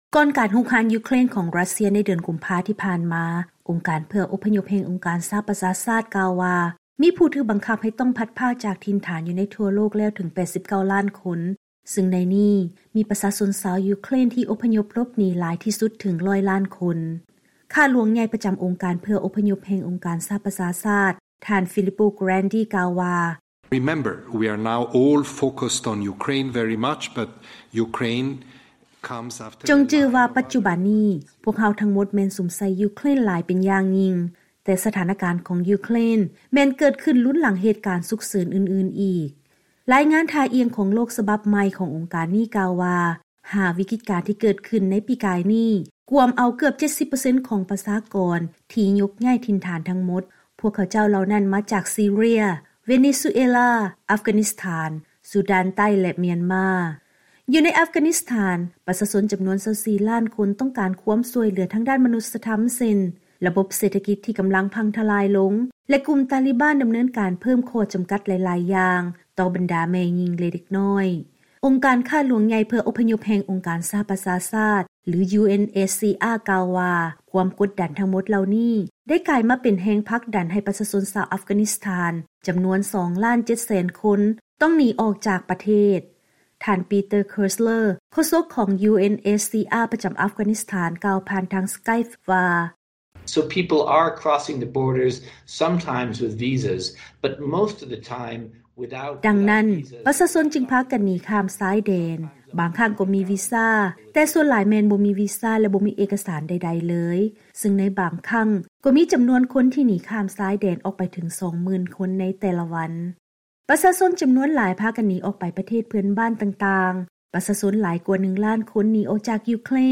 ເຊີນຮັບຟັງລາຍງານກ່ຽວກັບ ວັນຜູ້ອົບພະຍົບໂລກ